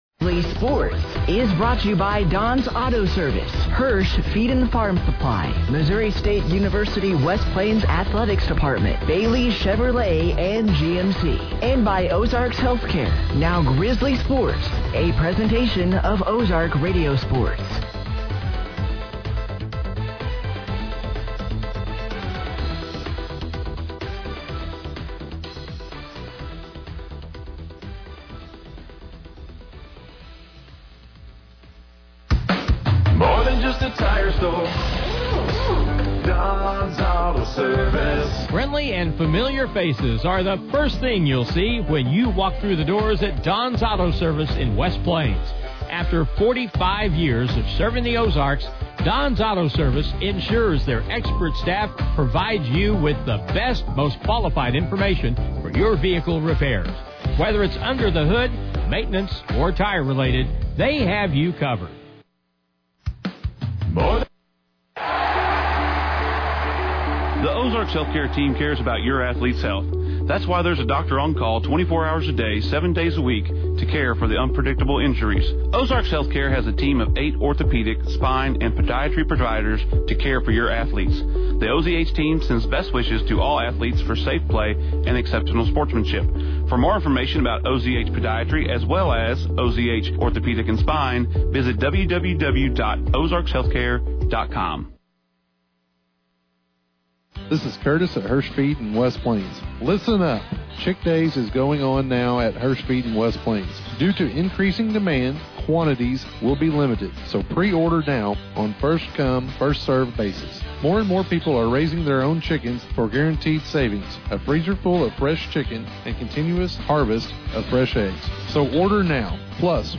Game Audio Below: The Missouri State West Plains Grizzlies traveled to the campus of St Louis Community College to take on The St Louis Archers on Wednesday Night, February 18th, 2026.
Missouri-State-West-Plains-Grizzlies-vs.-St.-Louis-Archers-2-18-26.mp3